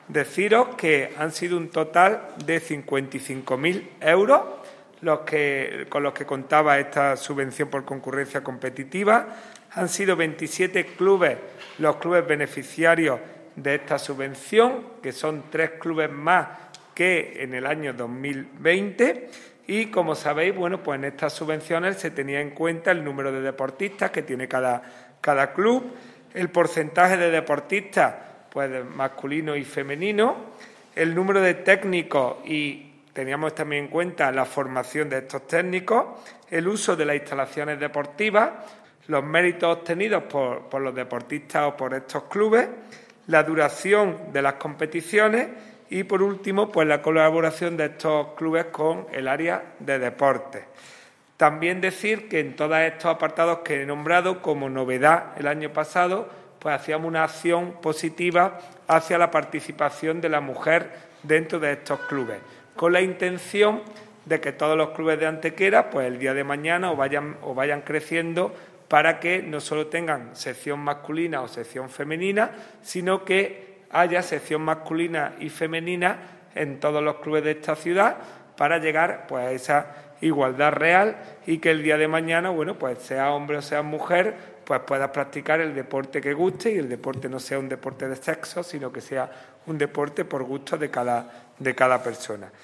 El teniente de alcalde delegado de Deportes, Juan Rosas, ha informado en rueda de prensa sobre las subvenciones municipales por concurrencia competitiva que ha otorgado el Ayuntamiento de Antequera en el año 2021 tanto a clubes deportivos como a deportistas jóvenes de alto nivel. 60.000 euros ha sido el total de las ayudas ya resueltas –el 80 por ciento de ellas ya están incluso ingresadas– que se han aportado desde el Consistorio para ayudar a competir tanto a 27 clubes (3 más que en 2020) y 8 deportistas individuales.
Cortes de voz